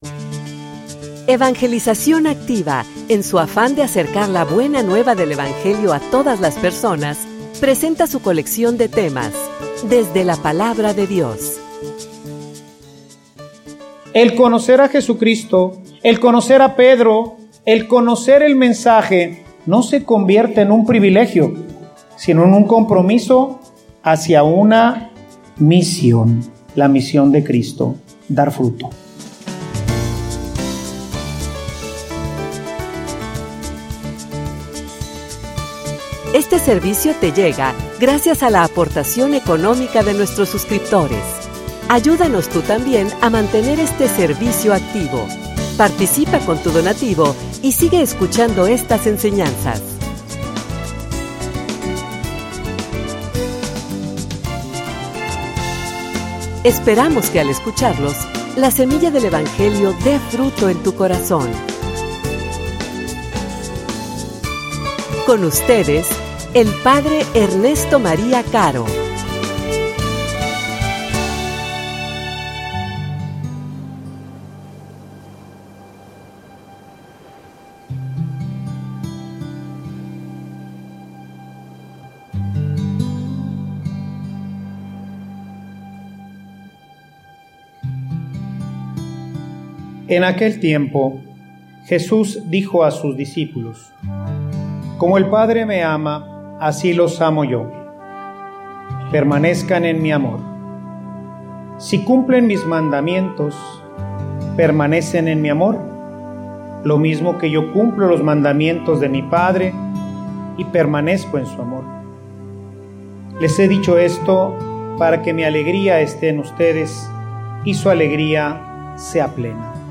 homilia_Escogidos_para_dar_fruto.mp3